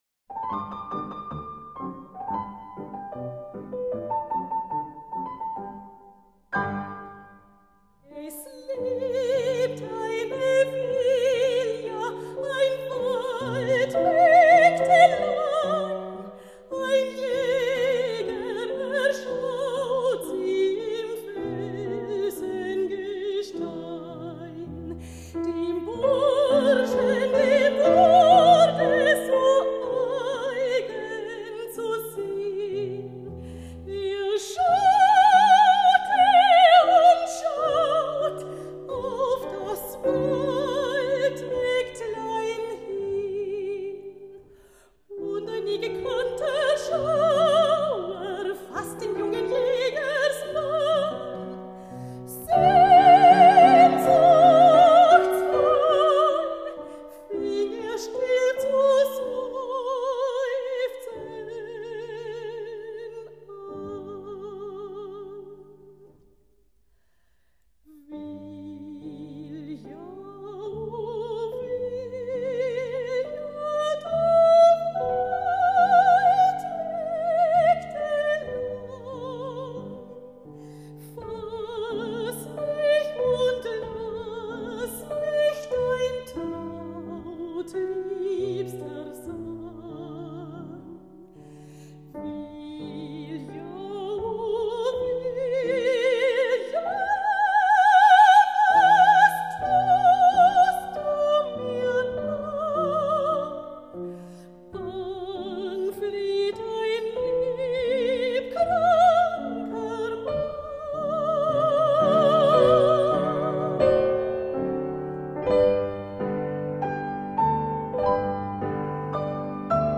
Operettenkonzert 2002 in Stansstad
Kirchenchor und Schützenchor Stansstad
im Gemeindesaal Stansstad    (klicken zum Ortsplan)
Sopran
Klavier